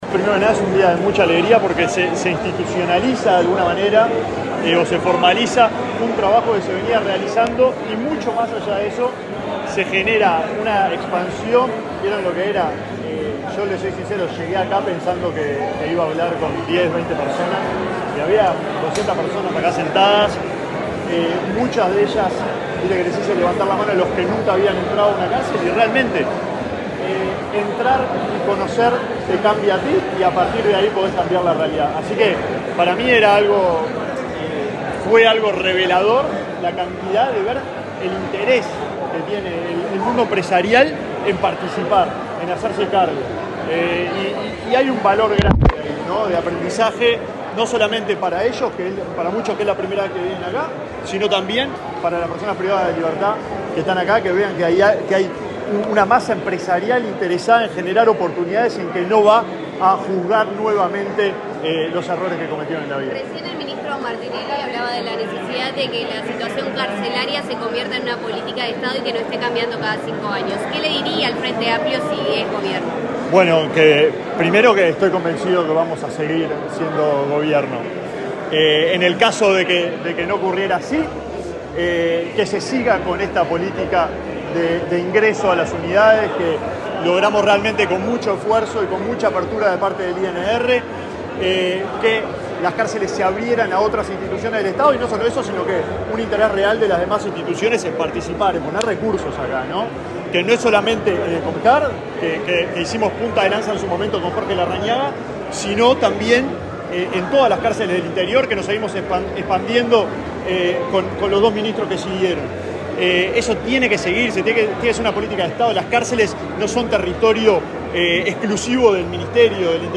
Declaraciones del ministro de Desarrollo Social, Alejandro Sciarra
El ministro de Desarrollo Social, Alejandro Sciarra, y su par del Interior, Nicolás Martinelli, participaron, este jueves 21 en el polo de Santiago Vázquez, en el acto de firma de un convenio con representantes de la Asociación de Dirigentes de Empresas para la inserción laboral de personas privadas de libertad. Luego, Sciarra dialogó con la prensa.